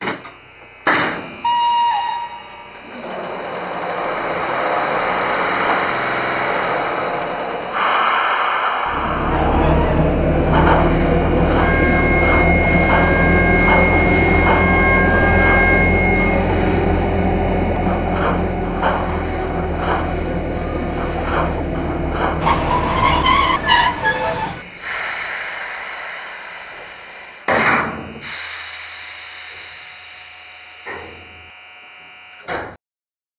• Digital-Decoder mfx+ mit umfangreichen Betriebs- und Geräuschfunktionen.